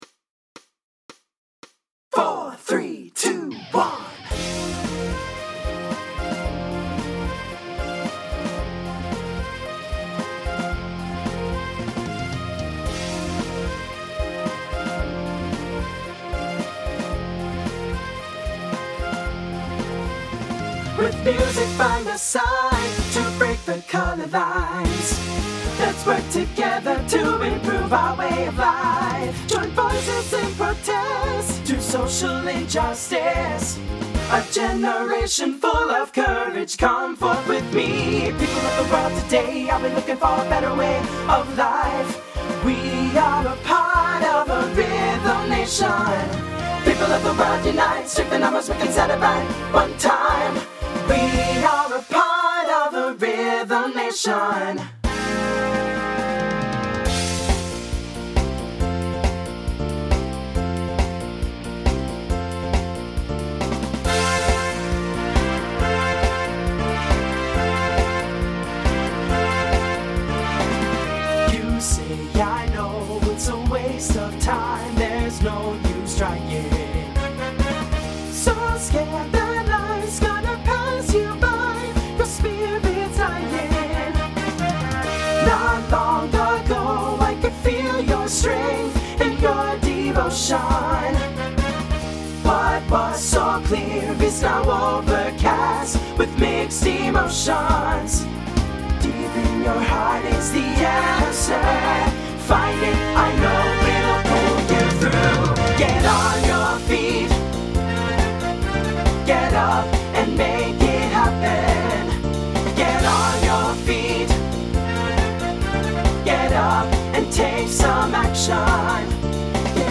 Voicing SATB Instrumental combo Genre Latin , Pop/Dance